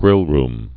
(grĭlrm, -rm)